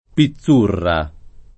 [ pi ZZ2 rra ]